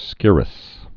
(skĭrəs, sĭr-)